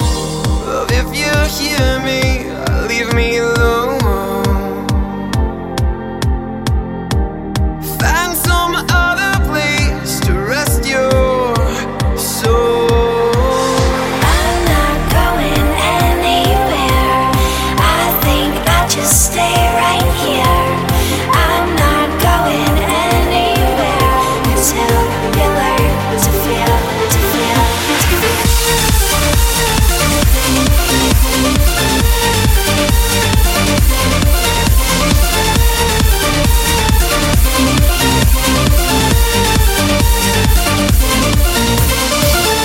Genere: dance, edm, club, remix